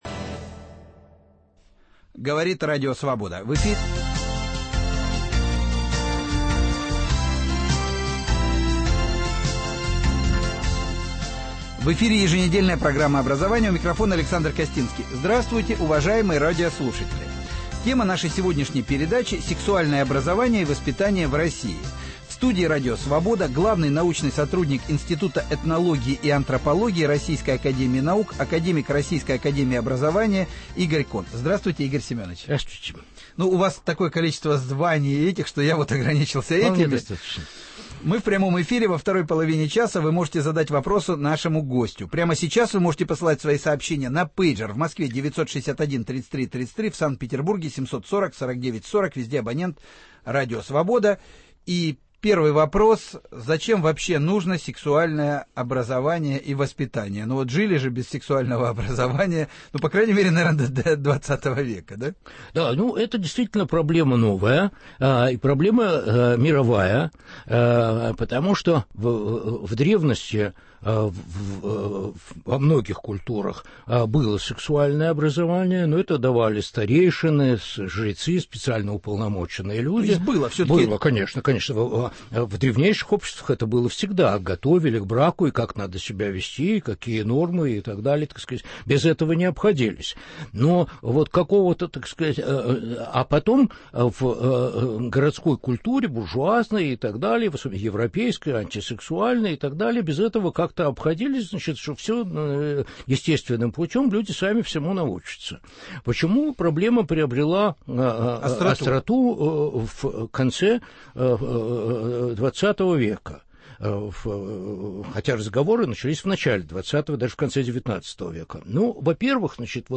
Сексуальное образование и воспитание в России. Гость Радио Свобода - главный научный сотрудник Института этнологии и антропологии Российской Академии наук, академик Российской академии образования Игорь Кон